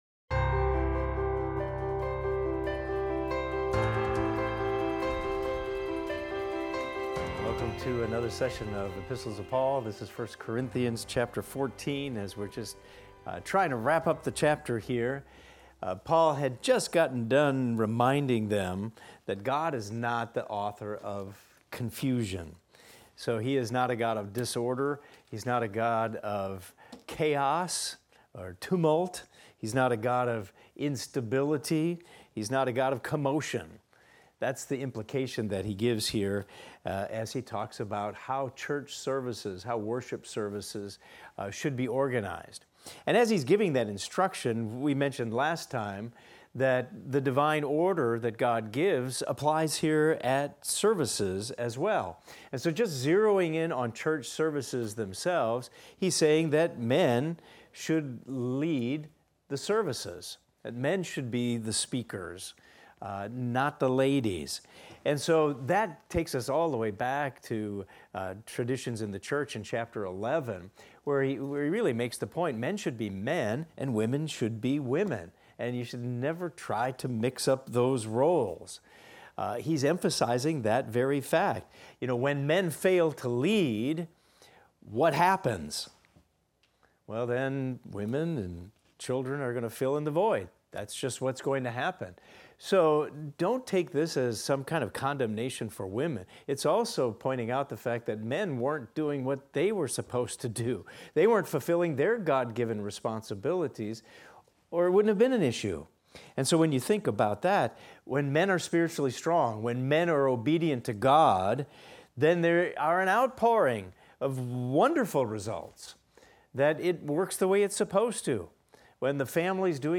In this class we will discuss 1 Corinthians 14:36 thru 1 Corinthians 15:31 and examine the following: Paul addresses various matters concerning order in worship and the resurrection.